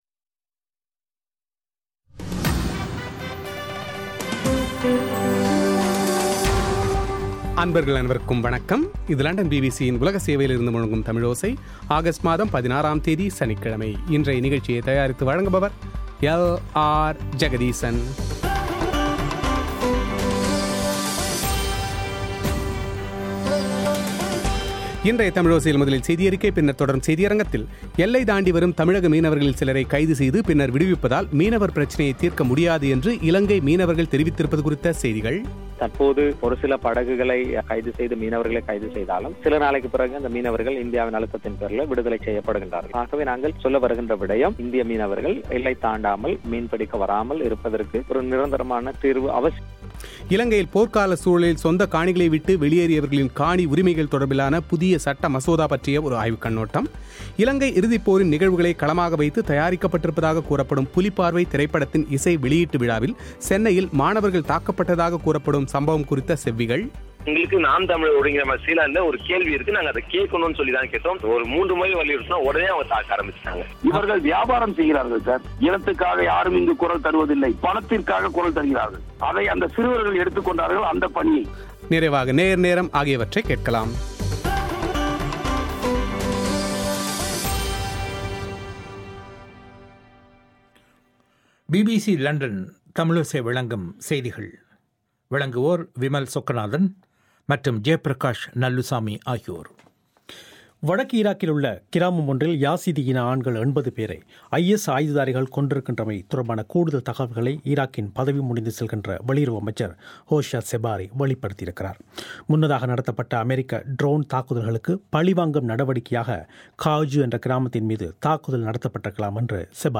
இலங்கையின் இறுதிப்போரின் நிகழ்வுகளை களமாக வைத்து தயாரிக்கப்பட்டிருப்பதாக கூறப்படும் புலிப்பார்வை திரைப்படத்தின் இசை வெளியீட்டுவிழாவில் சென்னையில் மாணவர்கள் தாக்கப்பட்டதாக கூறப்படும் சம்பவம் குறித்து இருதரப்பாரின் செவ்விகள்;